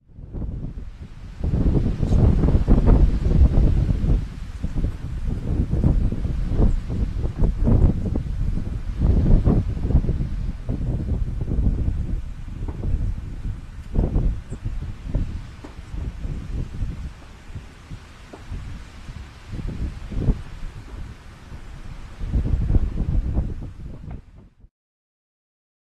American Golden-Plover  MOV  MP4  M4ViPOD  WMV